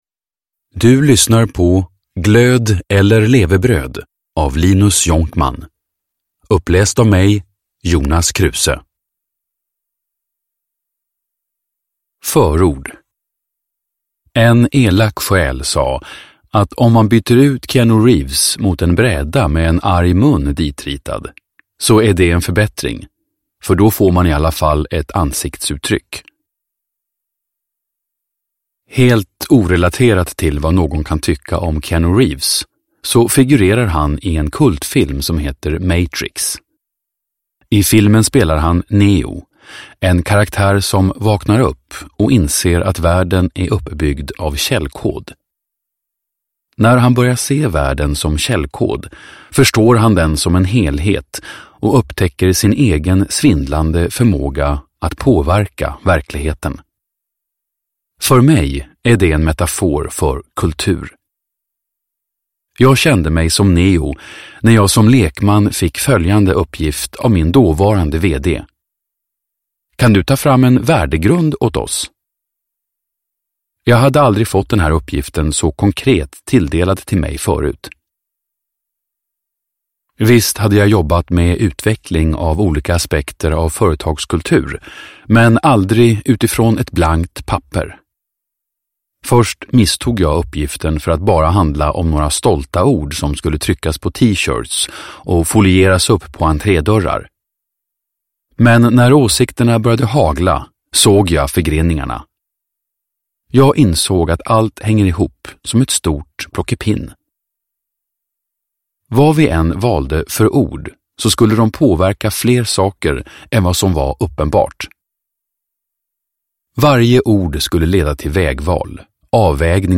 Glöd eller levebröd – om konsten att hitta meningen med jobbet – Ljudbok – Laddas ner